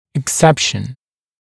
[ɪk’sepʃn] [ek-] [ик’сэпшн] [эк-] исключение